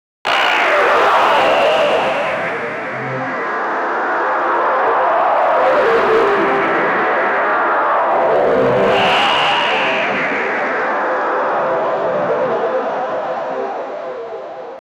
Royalty-free therapy sound effects
sound-therapy-sleep--4vdw5x4o.wav